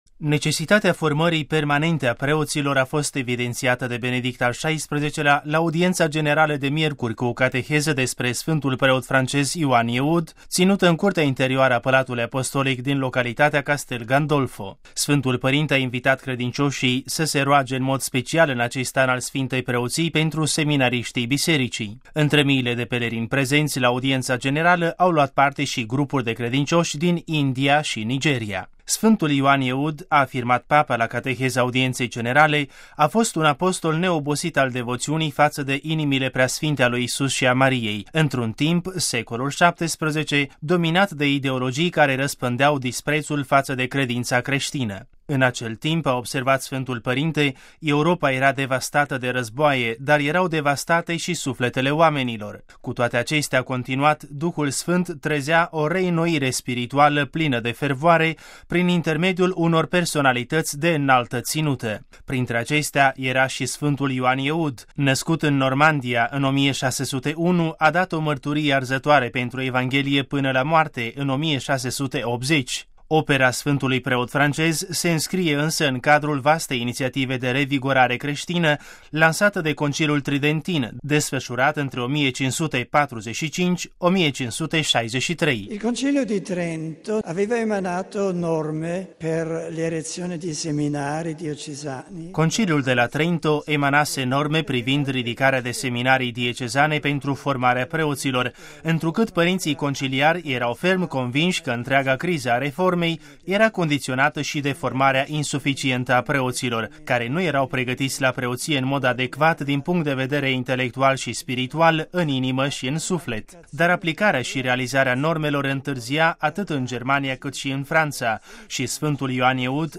Cu o cateheză despre sfântul preot francez Ioan Eudes, papa Benedict al XVI-lea a subiliniat necesitatea formării permanente a preoţilor, vorbind la audienţa generală de miercuri ţinută în curtea interioară a Palatului Apostolic din localitatea Castelgandolfo.
Aici, serviciul audio şi Binecuvântarea Apostolică: RealAudio